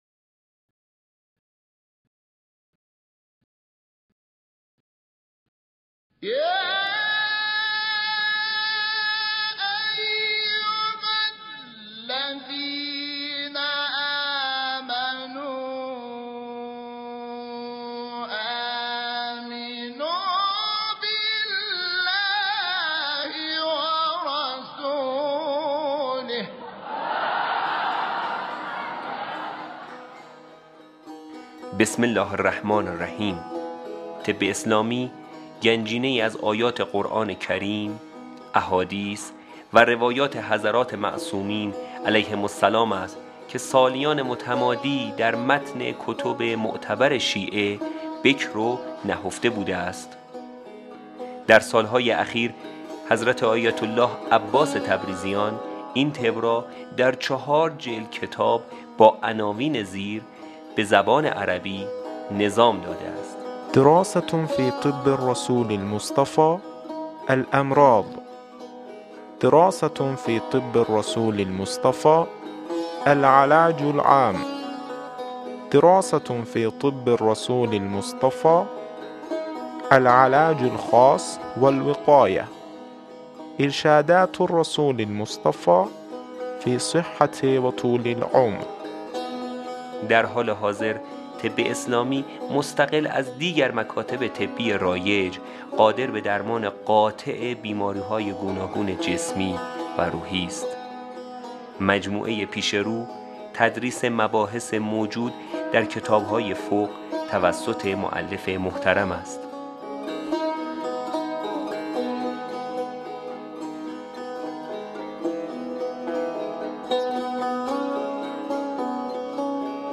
صوت تدریس جلد 2 ، جلسه 8